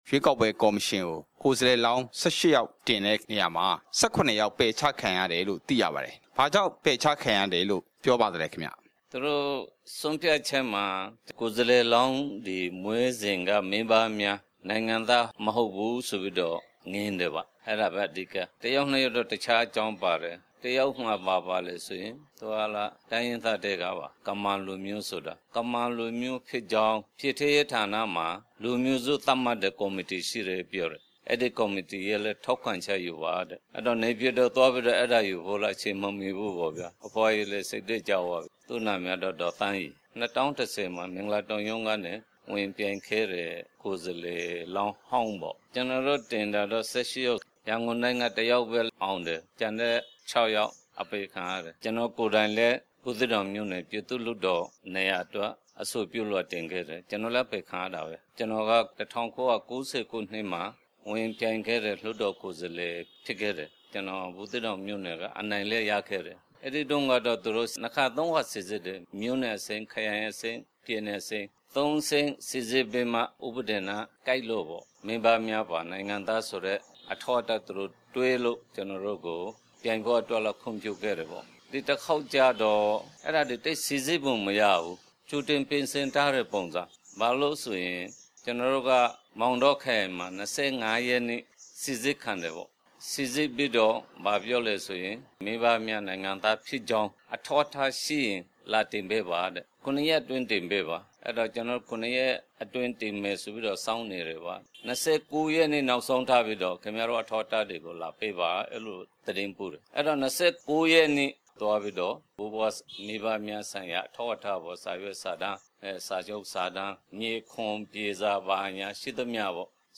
ကိုယ်စားလှယ်လောင်းအားလုံးနီးပါး ပယ်ချခံလိုက်ရတဲ့အတွက် ရွေးကောက်ပွဲမှာဝင်ရောက်ယှဉ်ပြိုင်ဖို့ သက်မှတ်ထားတဲ့ အနည်းဆုံး မဲဆန္ဒနယ်မြေ ၃ နေရာ မပြည့်မှီတော့ဘူးလို့ ဥက္ကဌ ဦးကျော်မင်းက RFA က မေးမြန်းစဉ်မှာ ပြောသွားတာပါ။